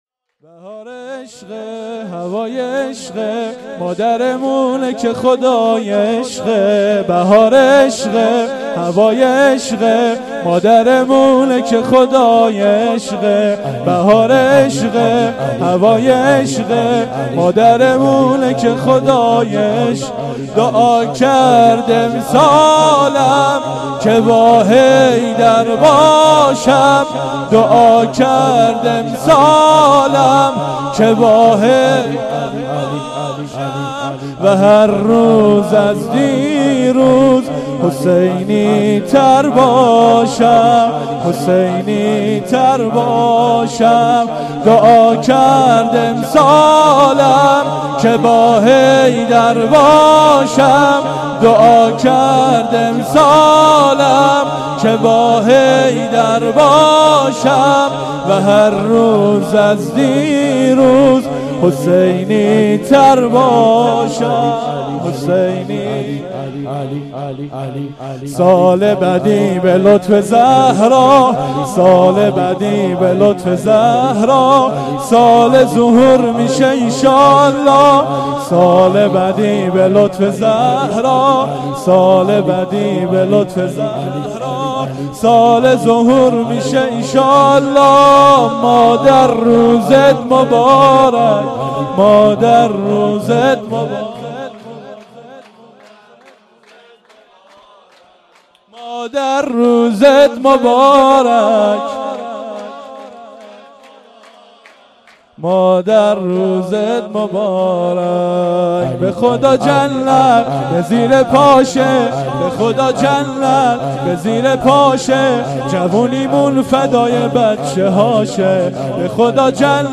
شور«2»
شور2.mp3